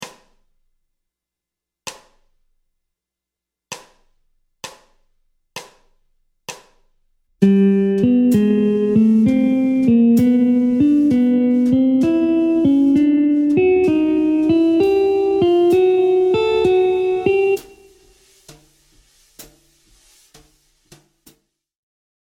Troisième des positions de gamme, choisie pour un jeu ‘Bop’ sur les gammes usuelles.
Schéma de départ : Gamme Majeure ( I – mode Ionien)
Montée de gamme
Gamme-bop-asc-Pos-42-G-Maj.mp3